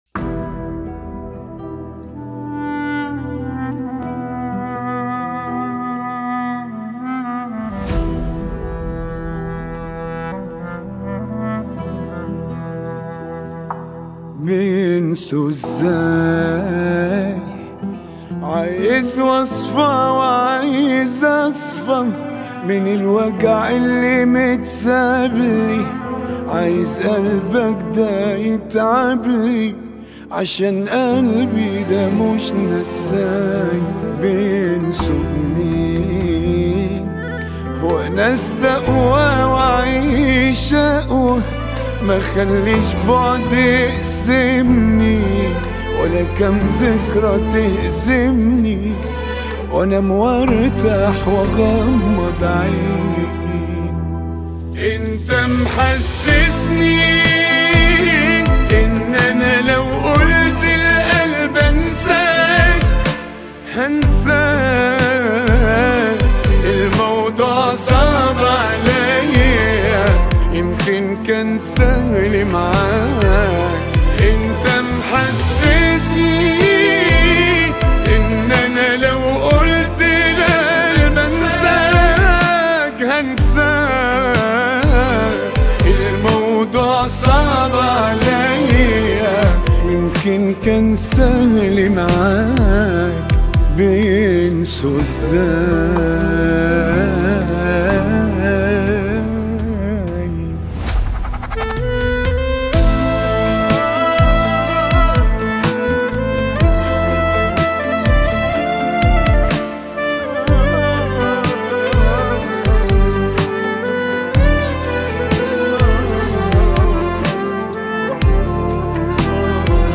واحدة من أقوى الأغاني الحزينة
• صوت مليء بالألم
• إحساس صادق جدًا